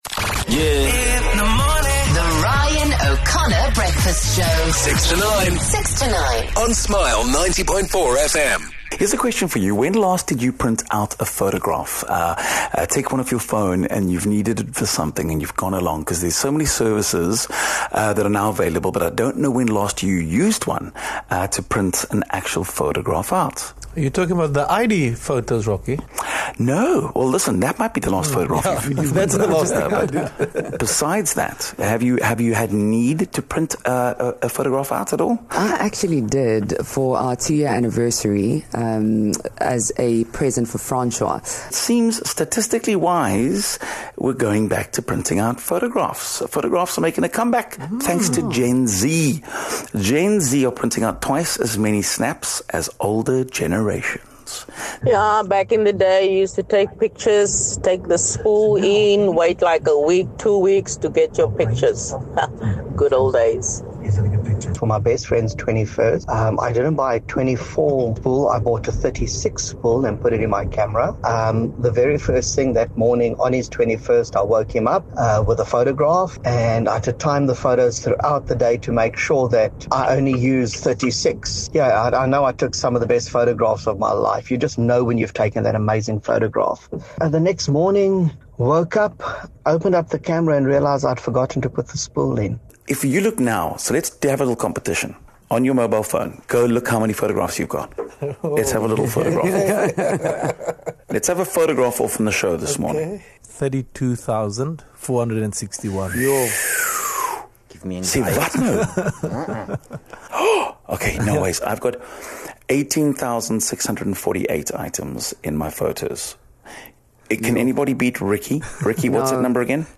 We spoke to a listener who admitted to having a digital hoarding problem.